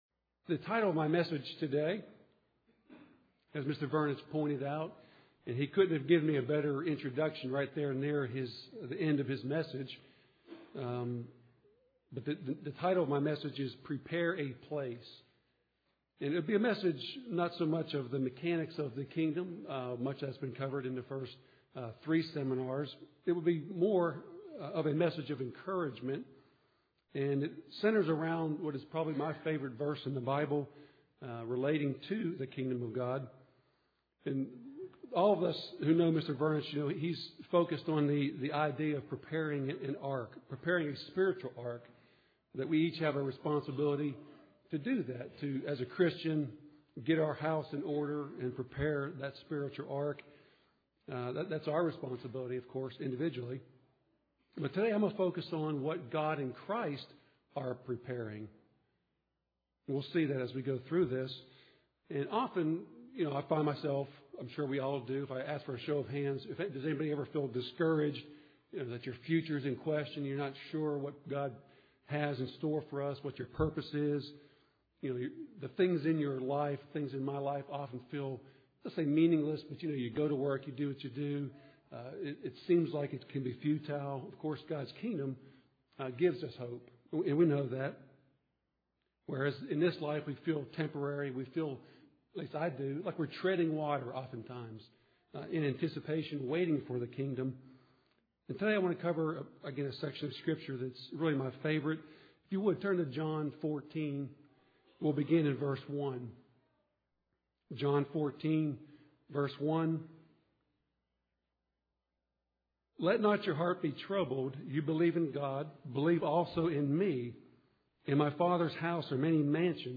This sermon focuses on the things Jesus said that He and the Father are doing to prepare a place for Christians in the Kingdom.
UCG Sermon Studying the bible?